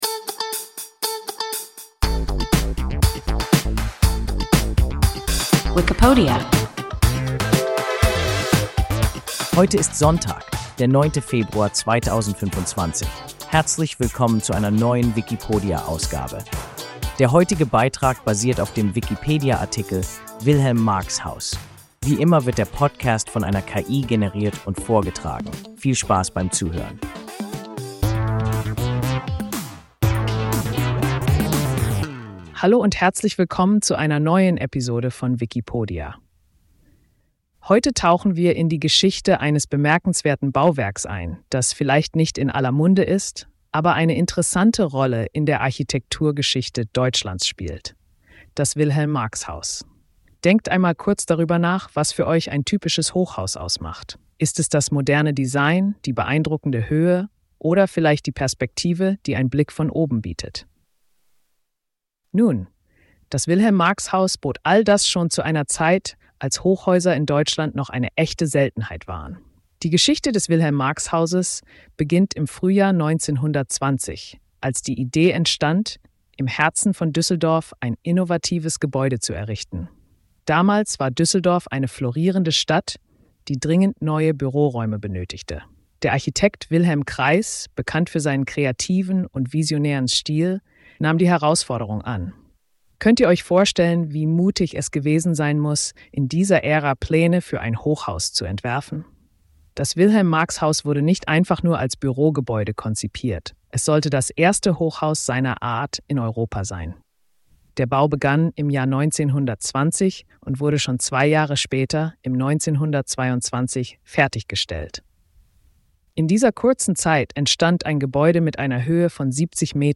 Wilhelm-Marx-Haus – WIKIPODIA – ein KI Podcast